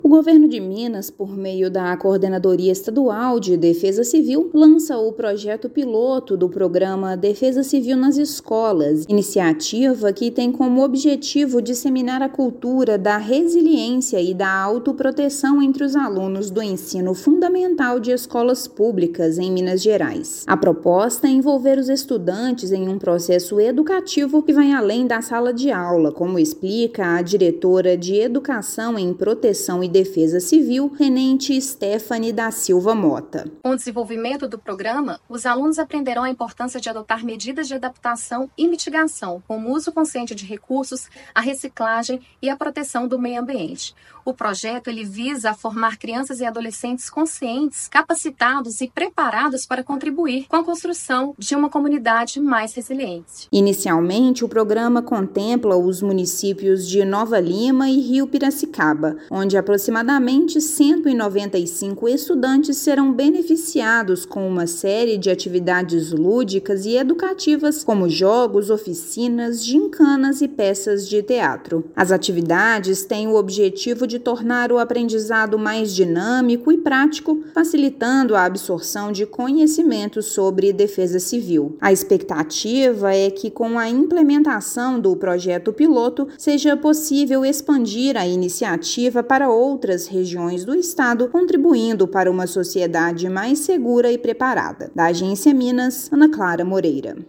Expectativa é que a primeira etapa da iniciativa, promovida pela Defesa Civil do estado, capacite mais de 190 alunos em escolas de dois municípios. Ouça matéria de rádio.